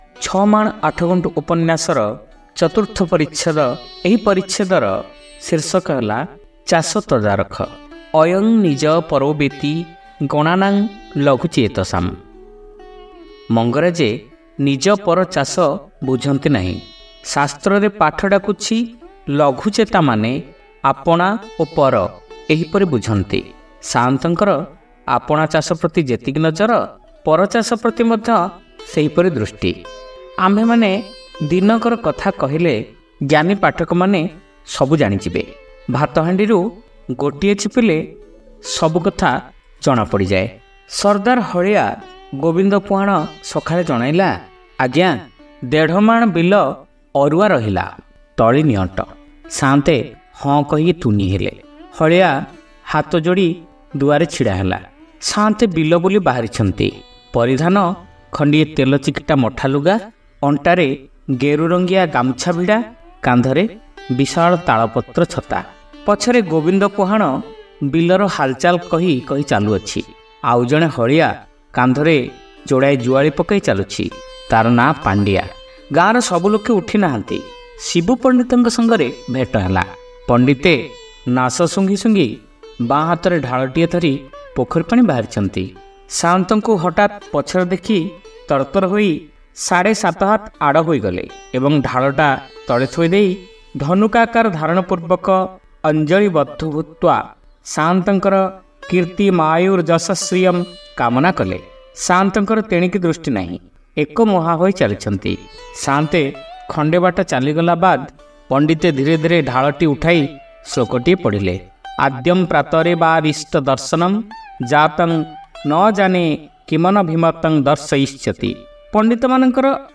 ଶ୍ରାବ୍ୟ ଉପନ୍ୟାସ : ଛମାଣ ଆଠଗୁଣ୍ଠ (ତୃତୀୟ ଭାଗ)